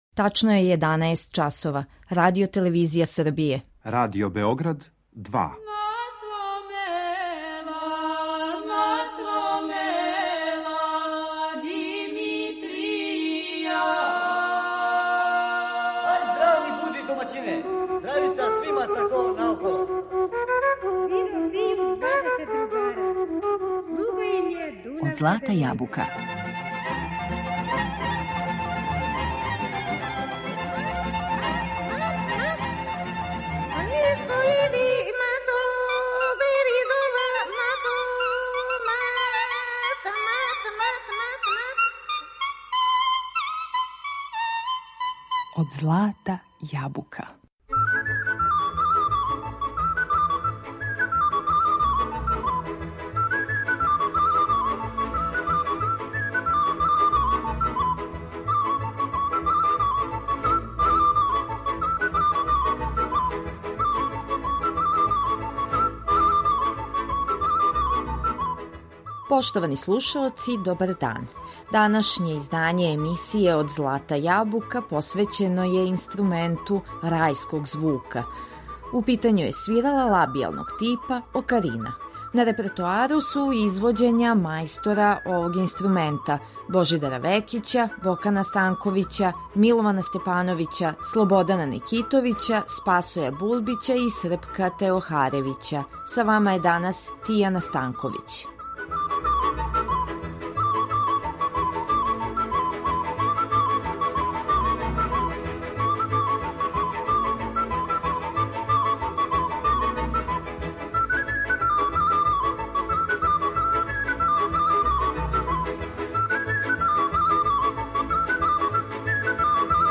Окарина